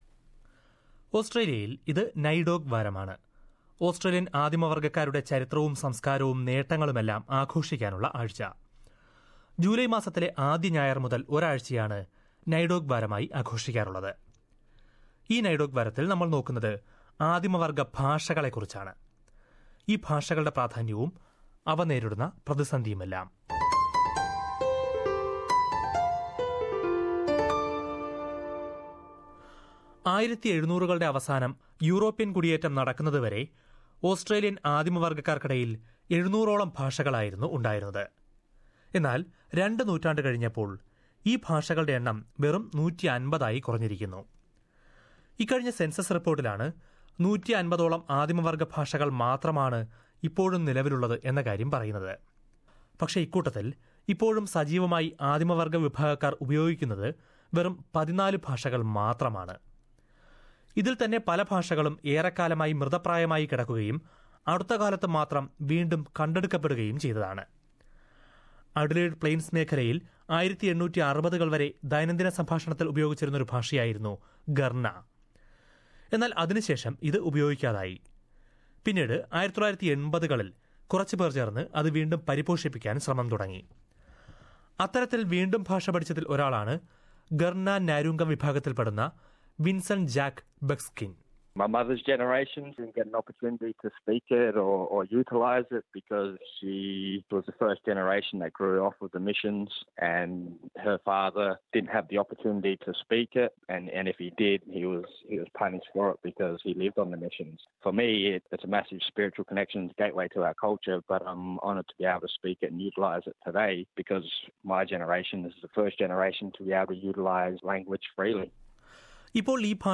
ഇവരുടെ ചരിത്രവും, സംസ്കാരവും നേട്ടവുമെല്ലാം ആഘോഷിക്കാനുള്ള NAIDOC വാരമാണിത്. ഇതുമായി ബന്ധപ്പെട്ട് ആദിമവർഗ്ഗക്കാരുടെ ഭാഷകളുടെ പ്രാധാന്യത്തെക്കുറിച്ചും അവ നേരിടുന്ന പ്രതിസന്ധികളെക്കുറിച്ചും ഒരു റിപ്പോർട്ട് കേൾക്കാം മുകളിലെ പ്ലേയറിൽ നിന്ന്...